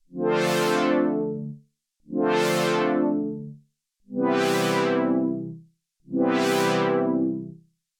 • The raw 60 oscillators have a touch more high end. Not even necessarily pleasant high end. Almost an alias-y quality.
Here are two “PWM+Saw+Sub+a touch of noise with a filter sweep and some resonance through chorus I” patches that I tried to get close to each other:
Juno 60 ACB: